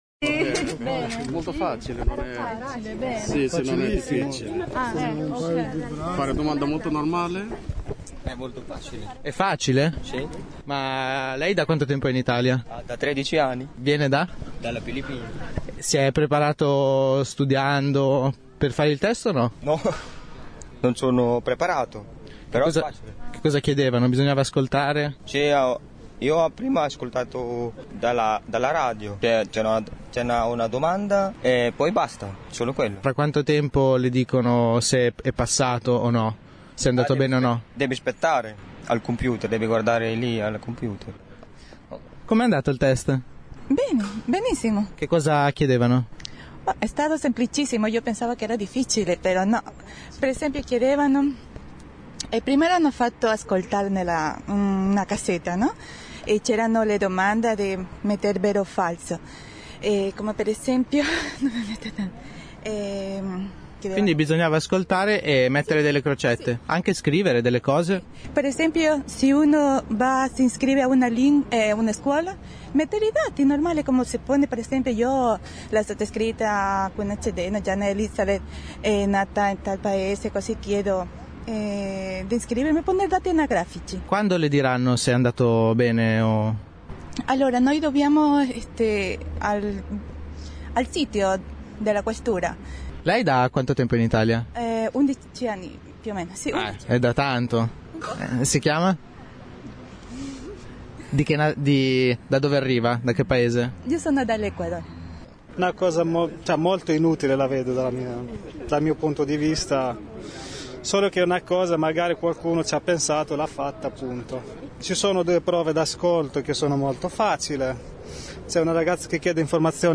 Ascolta le voci di coloro che hanno affrontato l’esame: